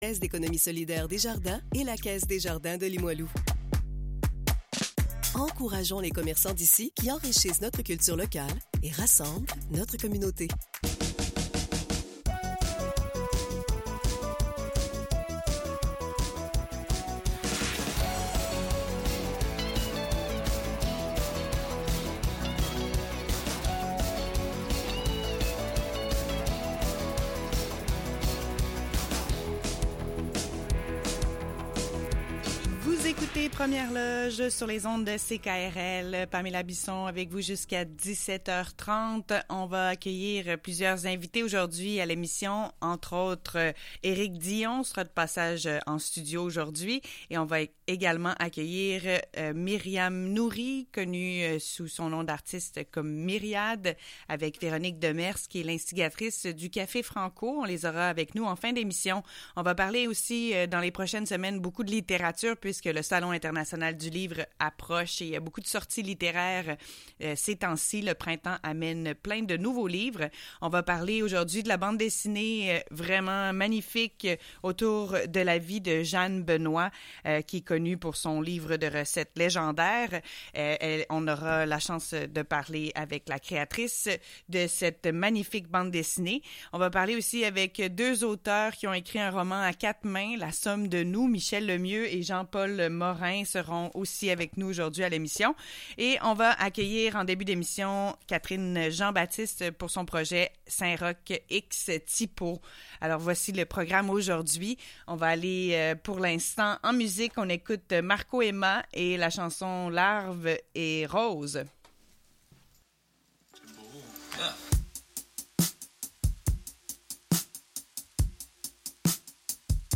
CKRL Entrevue